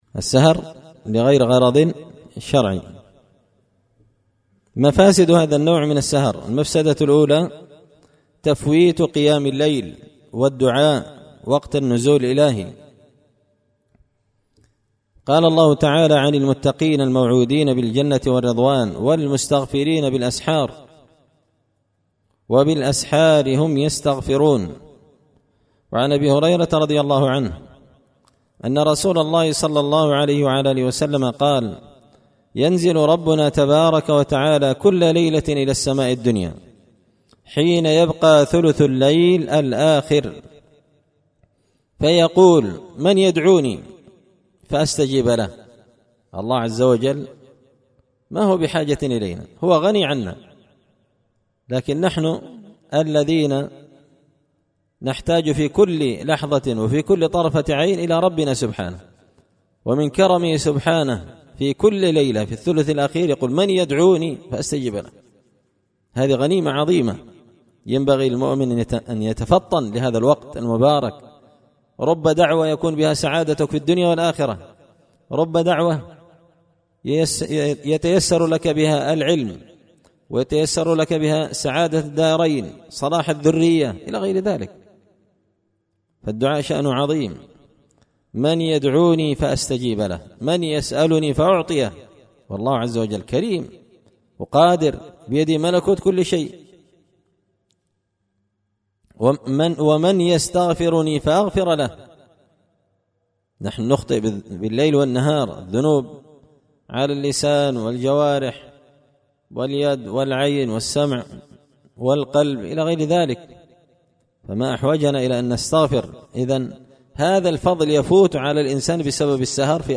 إمتاع النظر بأحكام السمر والسهر ـ الدرس الثالث عشر